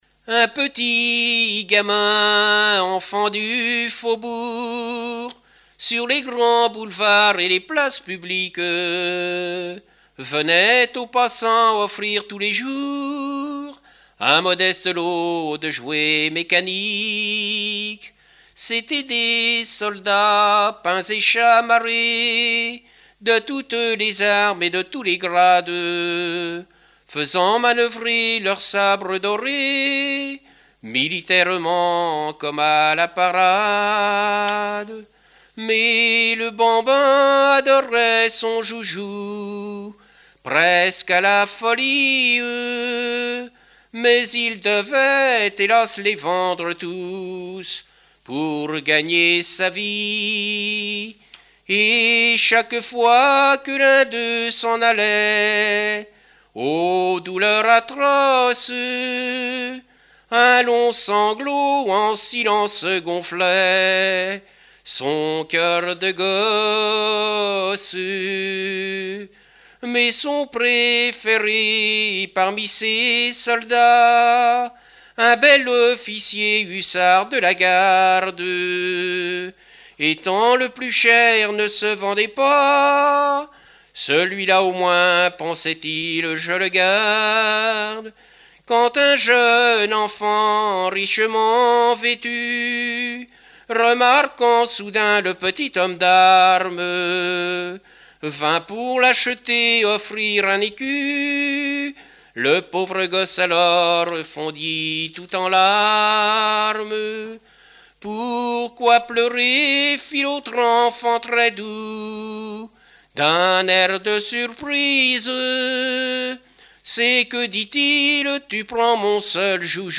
chansons de variété et music-hall
Pièce musicale inédite